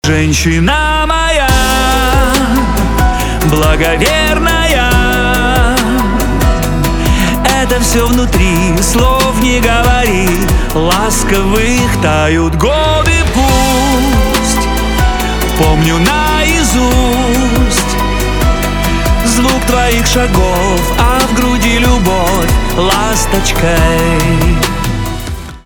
поп
романтические , чувственные , скрипка , пианино